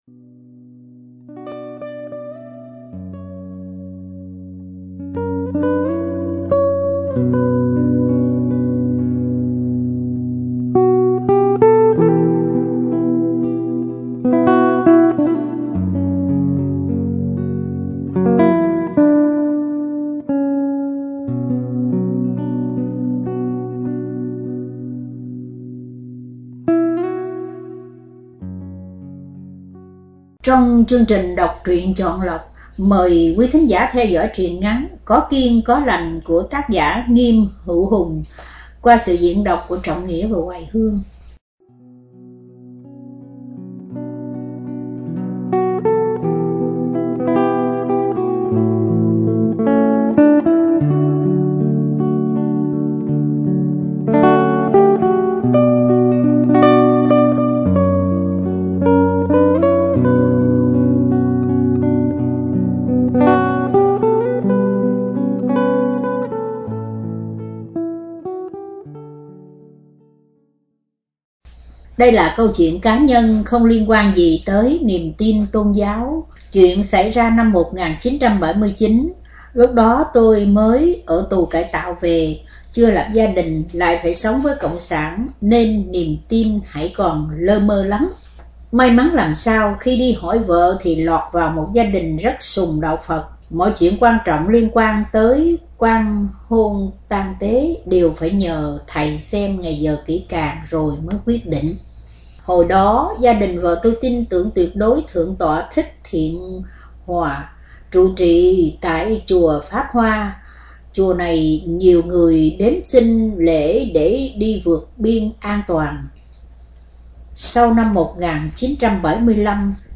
Đọc Truyện Chọn Lọc